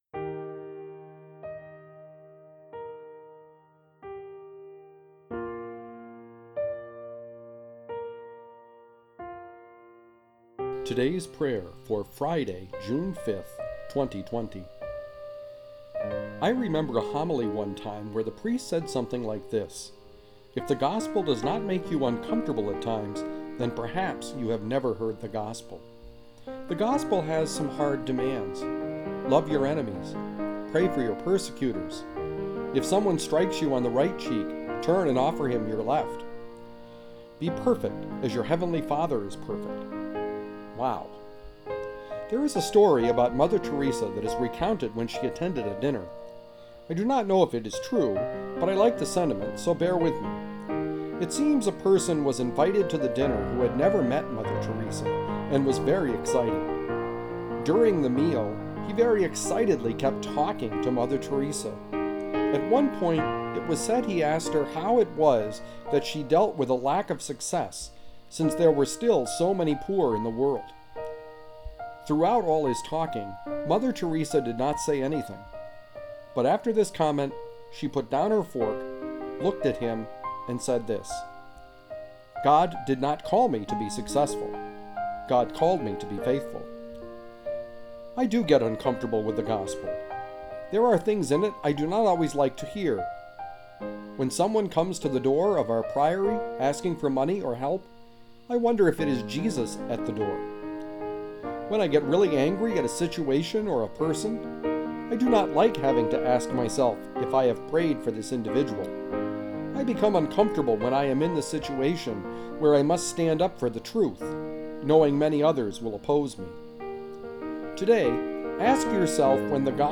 Cheezy Piano Medley